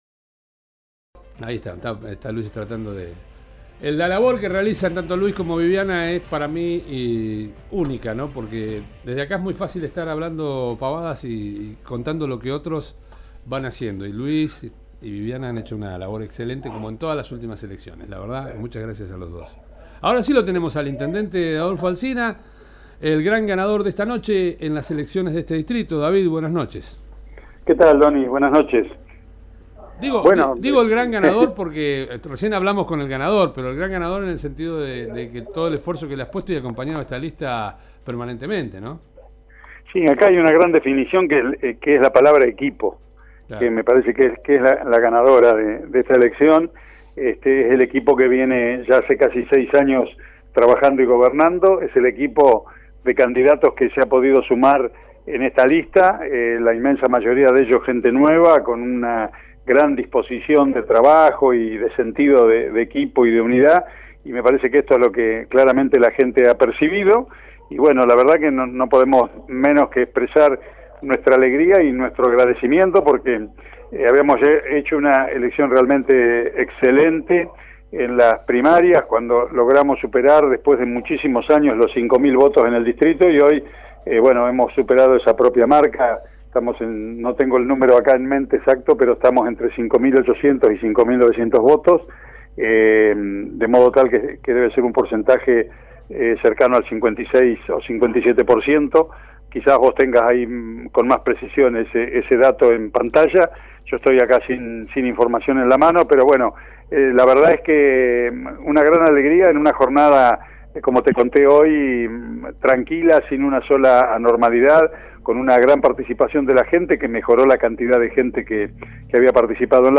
Entrevista al Intendente David Hirtz tras el amplio triunfo obtenido en las urnas
Alrededor de las 18:30 conversamos con el Jefe Comunal David Hirtz, quien como es habitual en sus declaraciones, se mostró sereno y reflexivo pero muy contento y agradecido con la comunidad de este distrito por el amplio apoyo obtenido, que no hizo otra cosa que reafirmar los resultados de las PASO de agosto pasado.